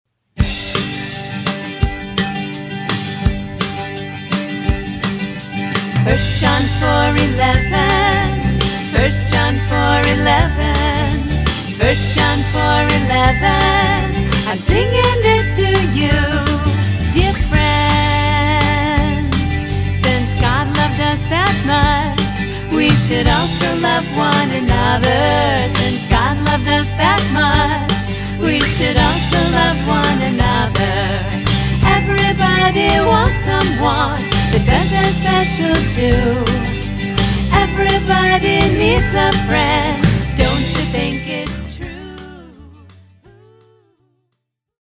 eclectic, contemporary Bible verse songs